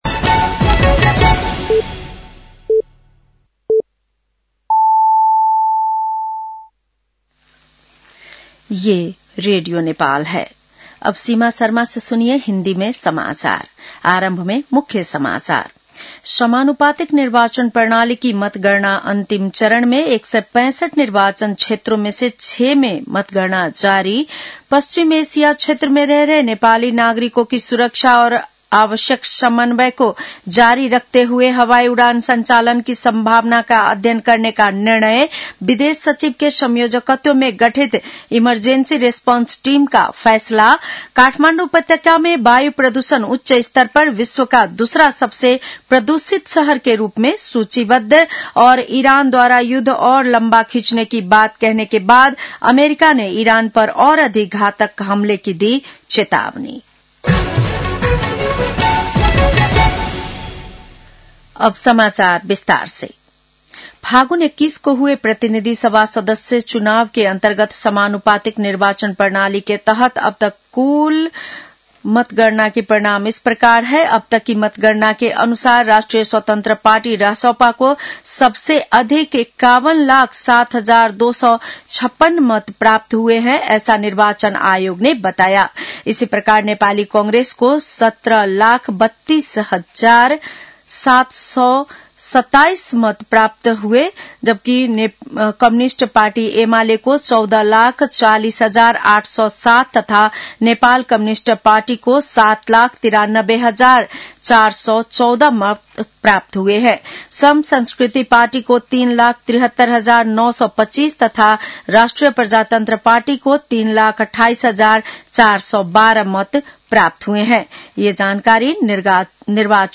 बेलुकी १० बजेको हिन्दी समाचार : २६ फागुन , २०८२